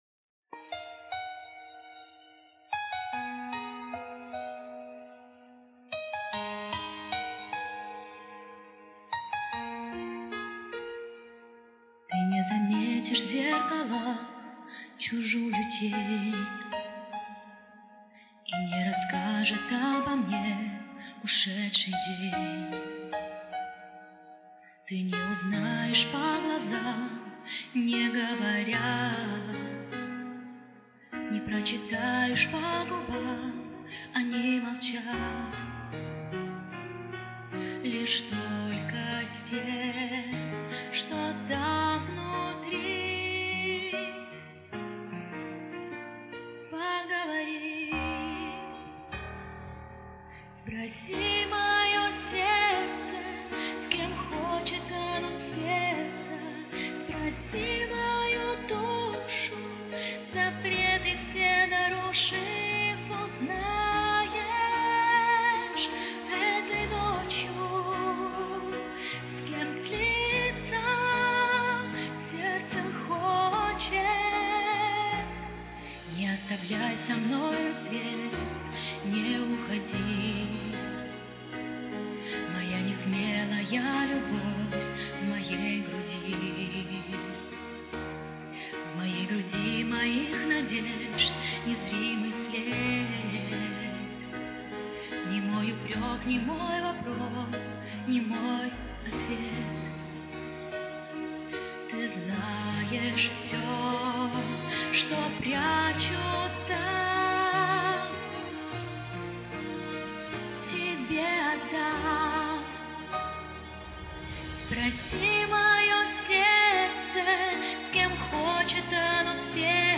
Поможет только смена микрофона..